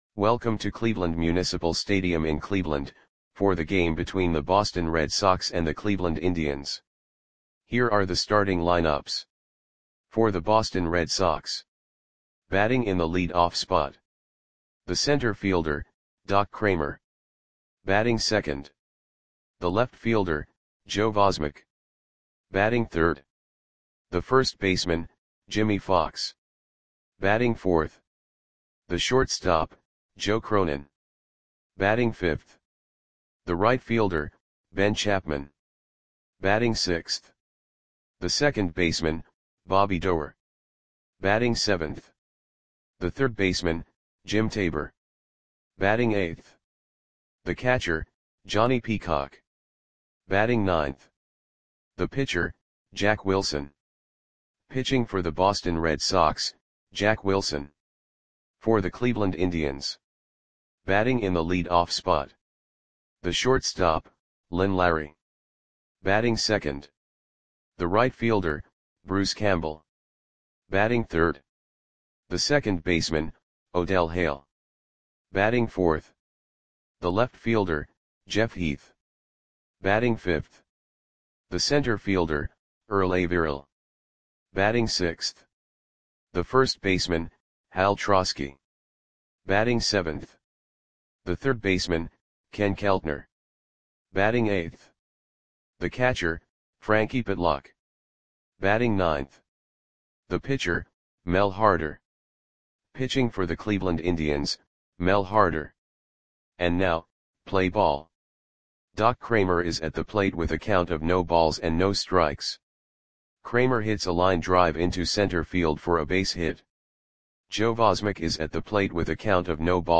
Audio Play-by-Play for Cleveland Indians on August 3, 1938
Click the button below to listen to the audio play-by-play.